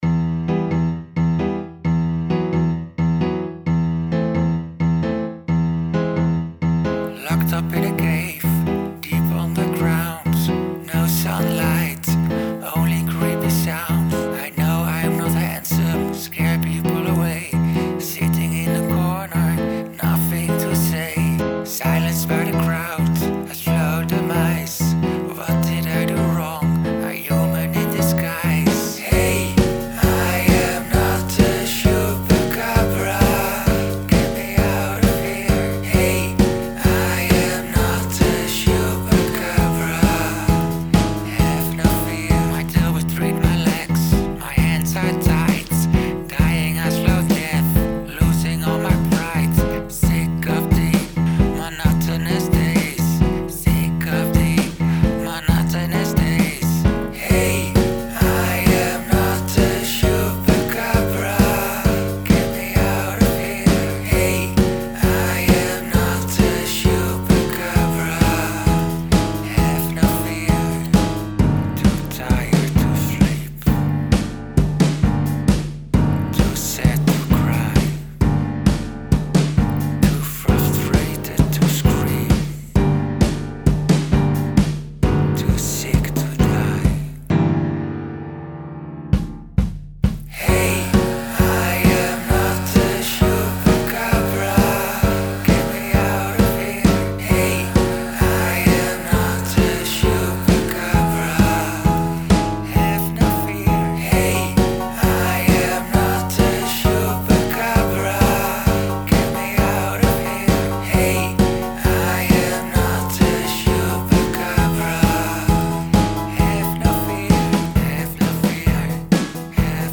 I like the rhythm of the piano, and your take on the title.